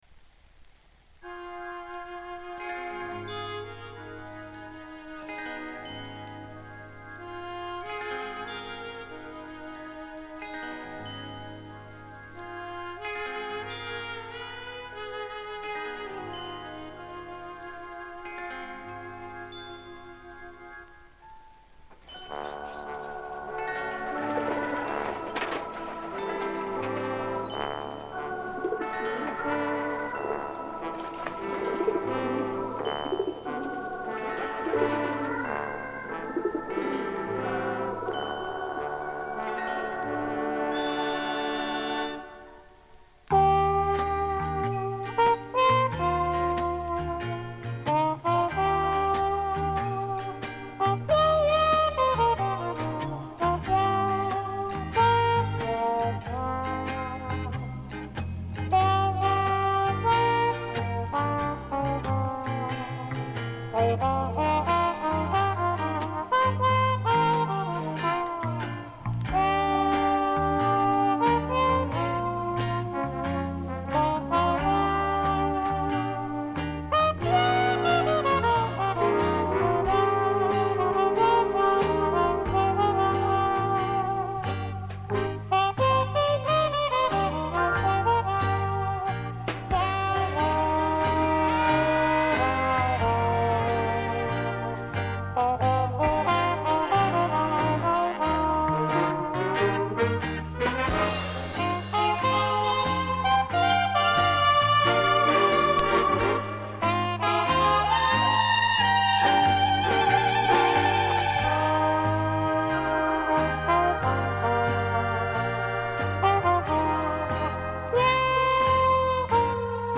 Музыкант-трубач из Чехословакии